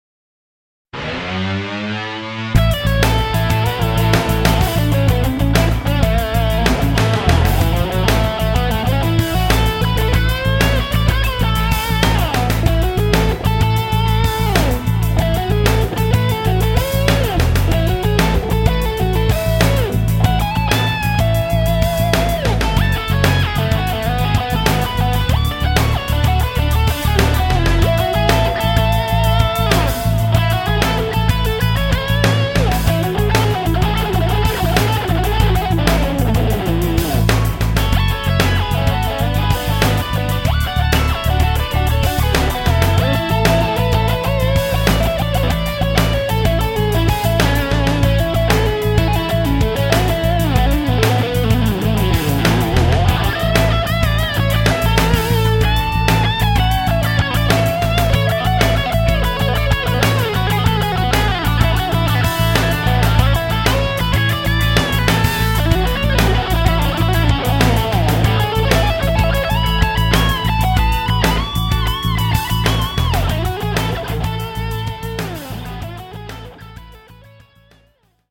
[Rock]